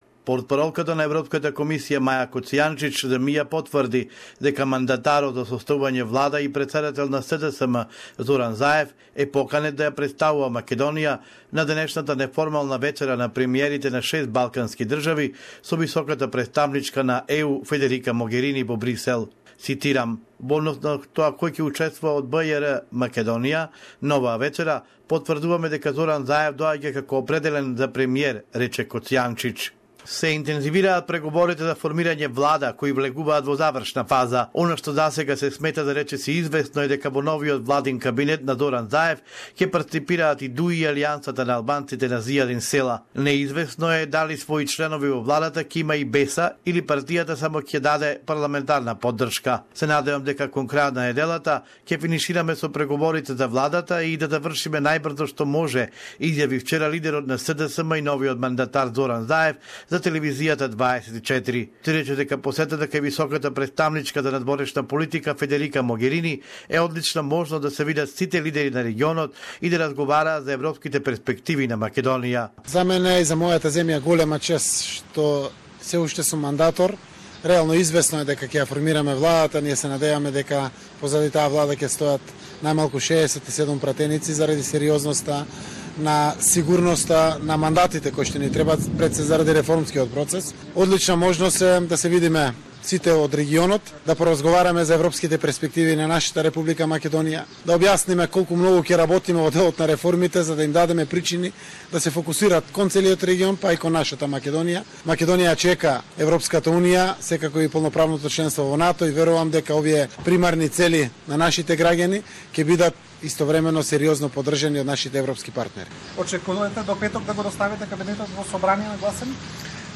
compiled this report from Macedonia